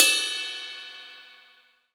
DJP_PERC_ (10).wav